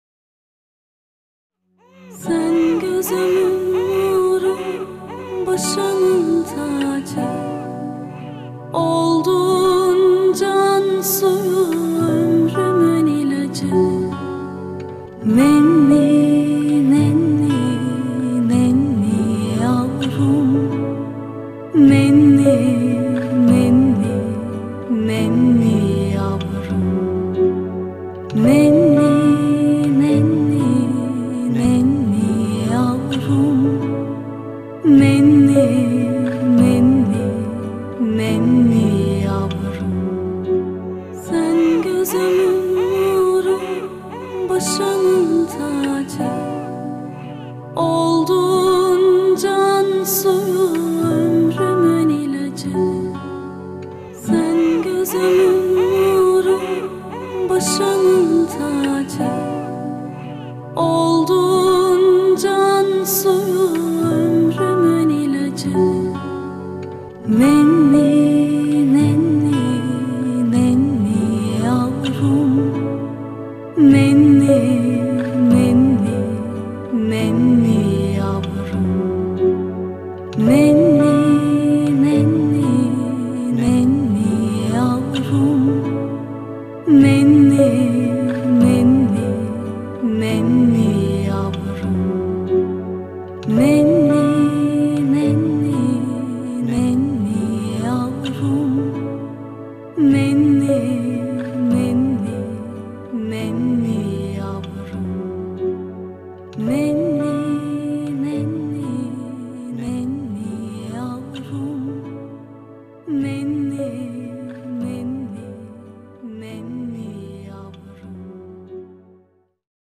duygusal huzurlu rahatlatıcı şarkı.